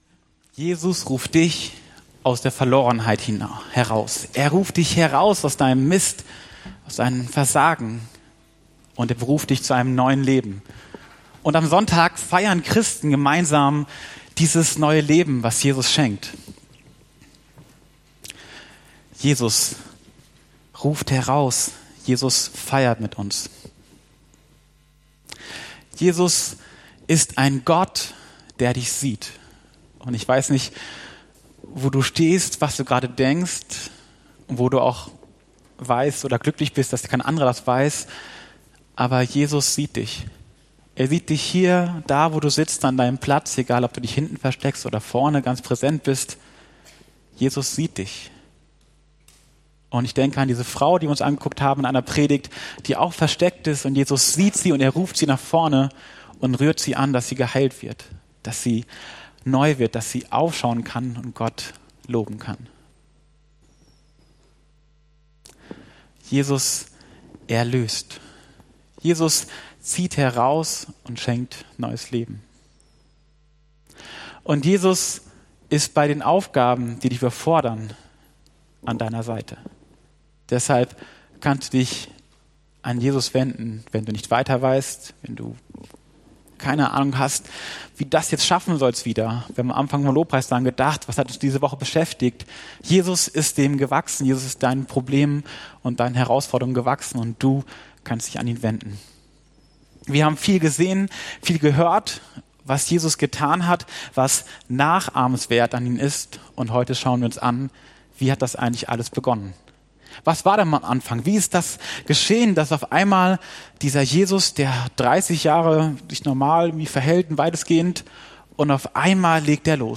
September 2021 Jesus Story TEIL VII Prediger(-in)